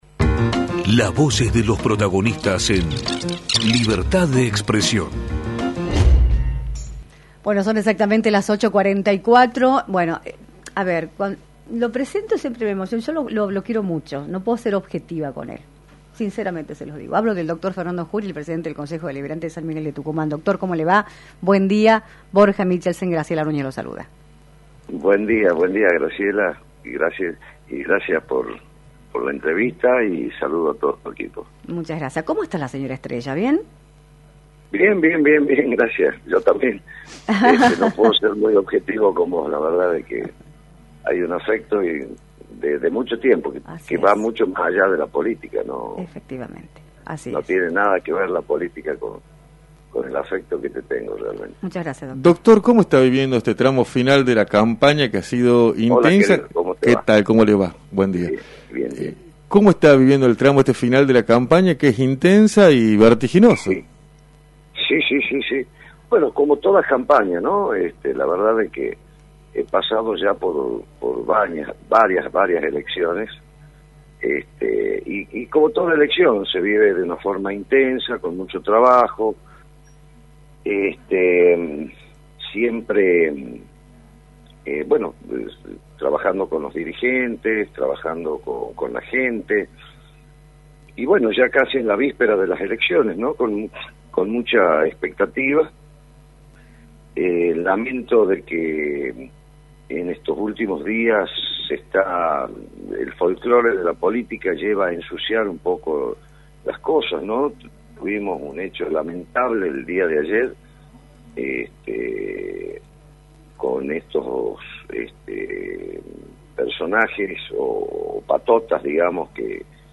Fernando Juri, Presidente del Honorable Concejo Deliberante de San Miguel de Tucumán, analizó en “Libertad de Expresión” el escenario político de la provincia y las repercusiones de la visita de Javier Milei a Tucumán.